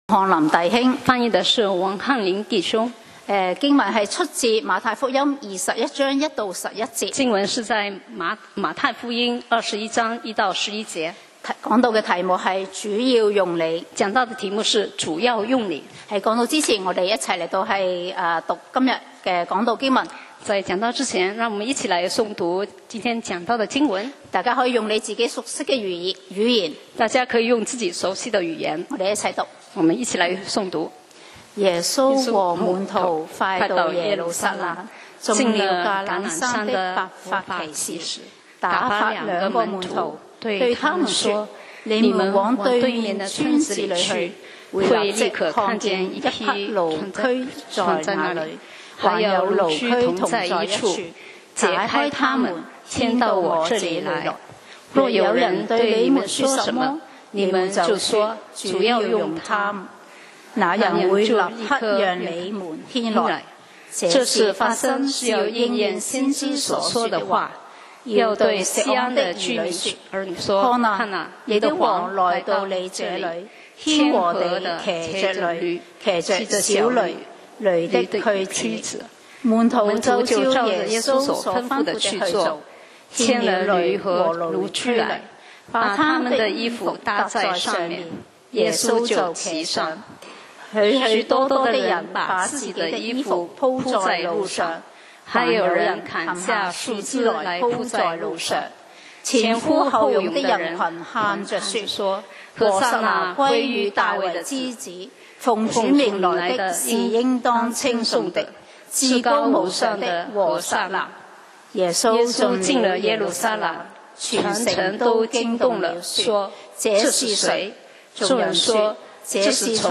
講道 Sermon 題目：主要用你！ 經文 Verses：馬太福音 21：1-11。